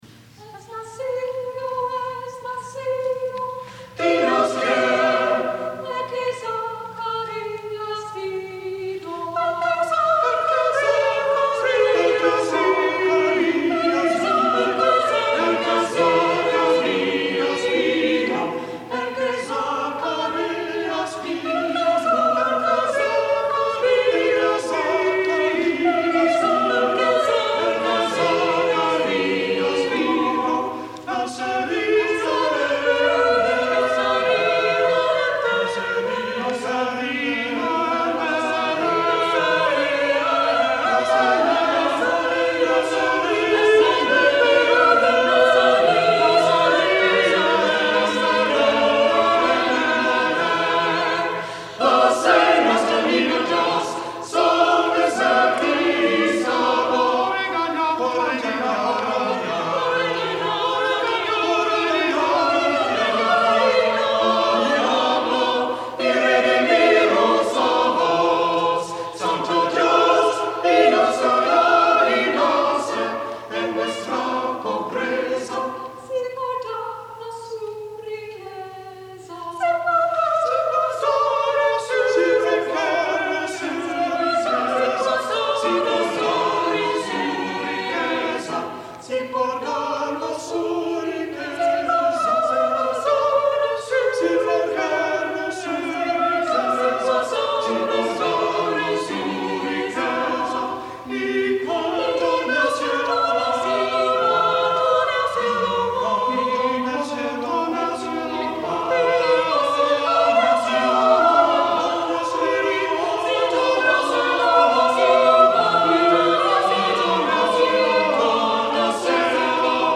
Our example of his work, a Christmas villancico, does appear to betray the City’s gross ignorance.